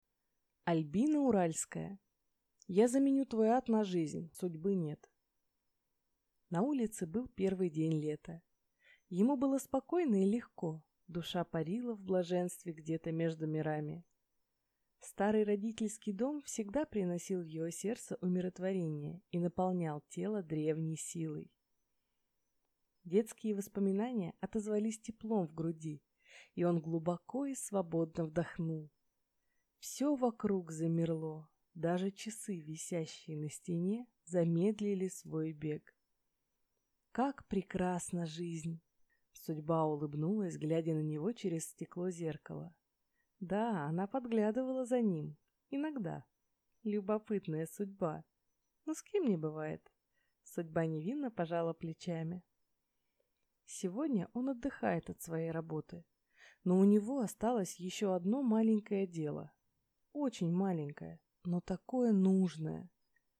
Аудиокнига Я заменю твой ад на жизнь… Судьбы нет | Библиотека аудиокниг